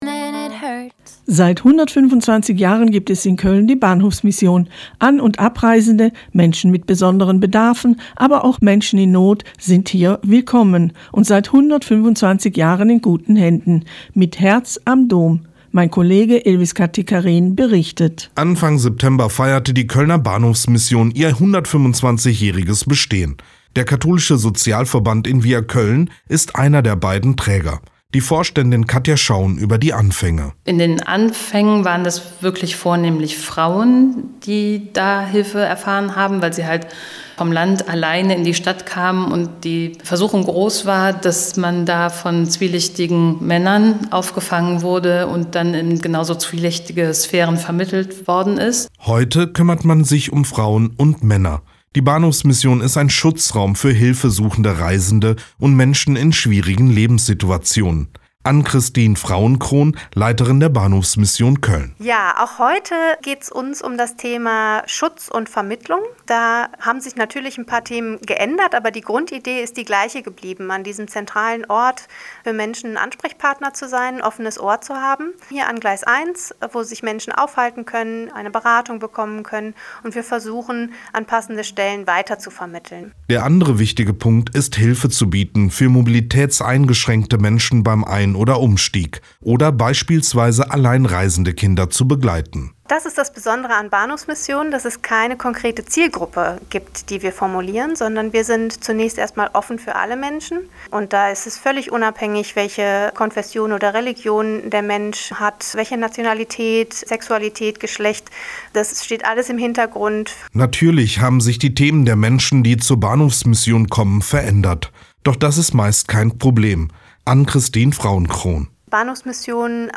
Tolles Radio-Feature über 125 Jahre Bahhofsmission Köln